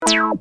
Free MP3 vintage Korg Polysix loops & sound effects 10
Korg - Polysix 173